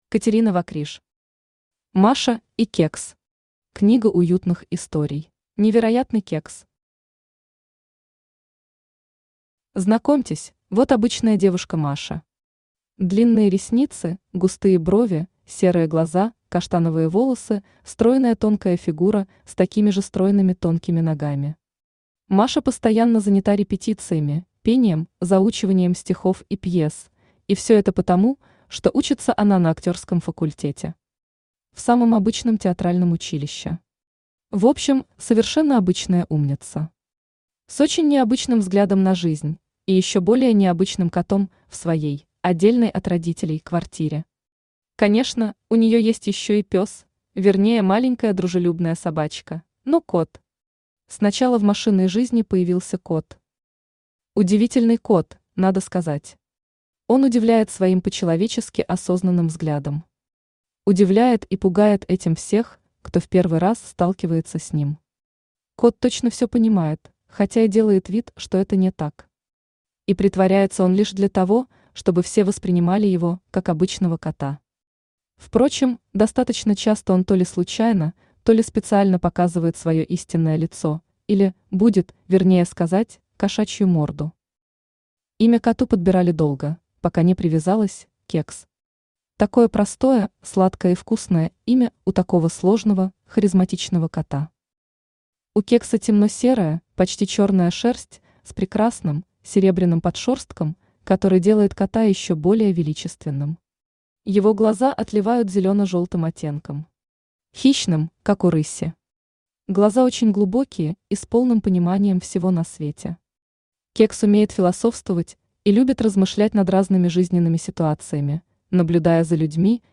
Аудиокнига Маша и Кекс. Книга уютных историй | Библиотека аудиокниг
Книга уютных историй Автор Катерина Вокриж Читает аудиокнигу Авточтец ЛитРес.